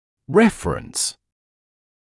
[‘refrəns][‘рэфрэнс] ссылка, упоминание; опорный, эталонный, контрольный